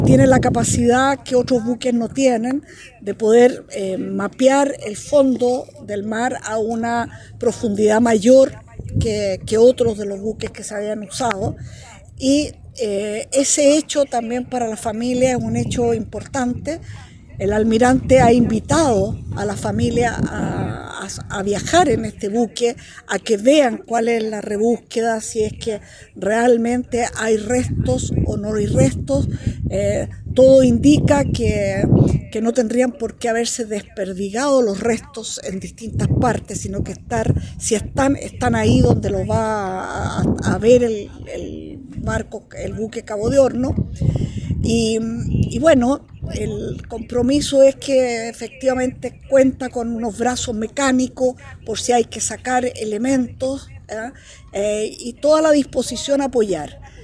En esa línea, la ministra explicó que esta nave cuenta con la capacidad de “mapear el fondo del mar a una profundidad mayor que otros buques que se han usado hasta ahora”.